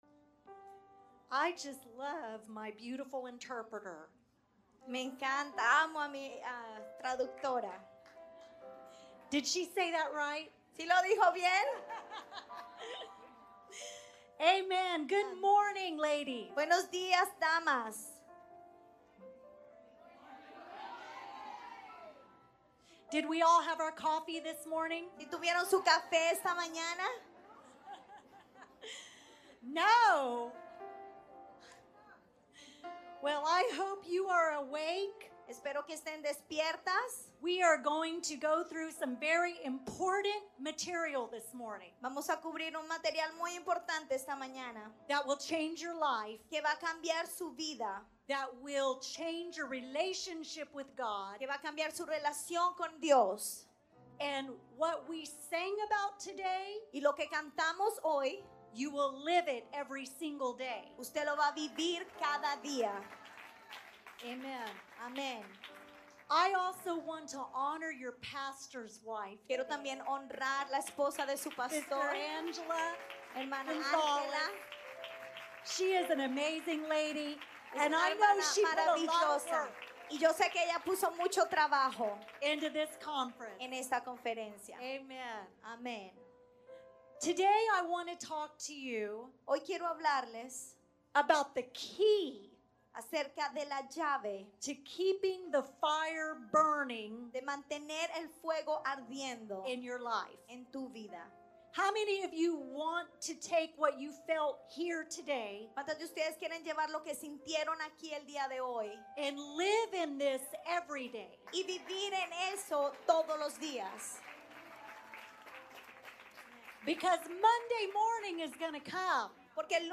Ladies' Conference Saturday Pt. 1